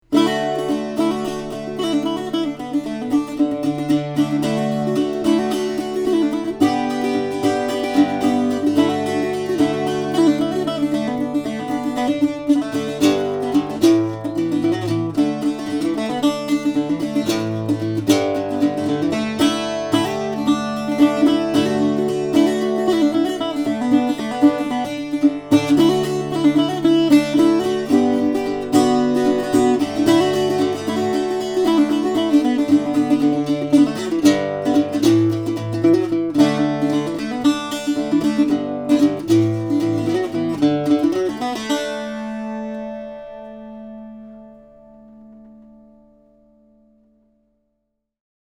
New Forster Bouzouki Cocobolo/Redwood - Dream Guitars
forster_bouzouki_2010.mp3